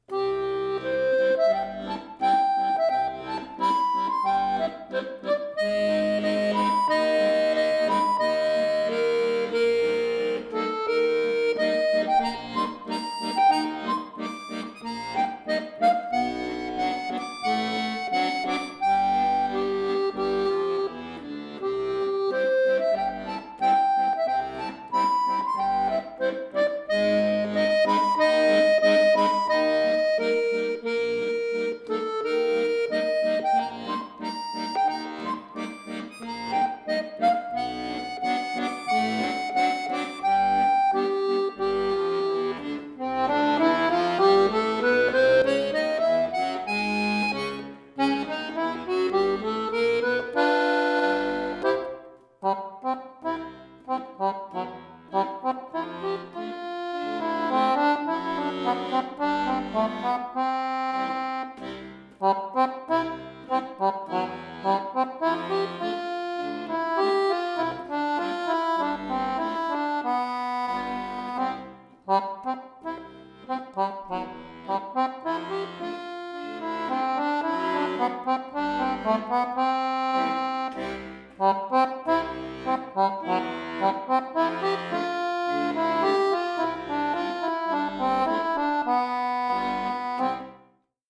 neu arrangiert für Akkordeon solo